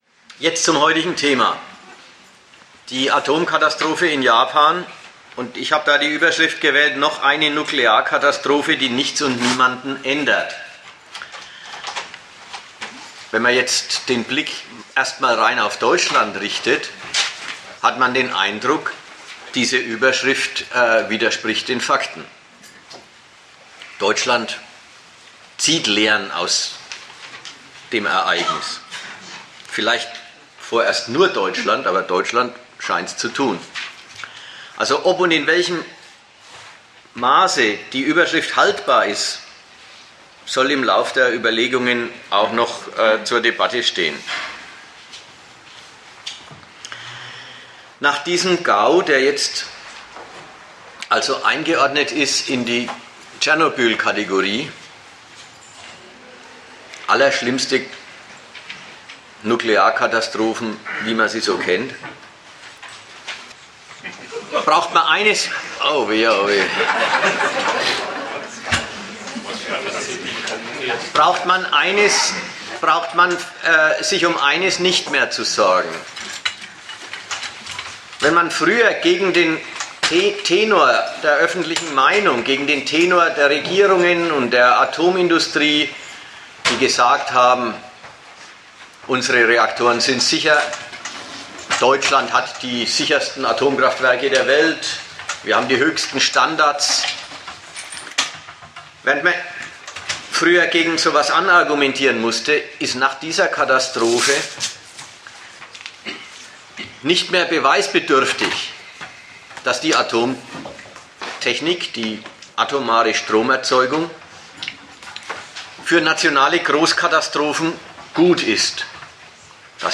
Ort Nürnberg
Dozent Gastreferenten der Zeitschrift GegenStandpunkt